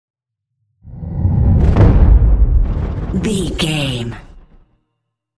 Chopper whoosh to hit large trailer
Sound Effects
Atonal
dark
intense
woosh to hit